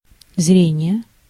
Ääntäminen
US : IPA : [ˈvɪʒ.ən]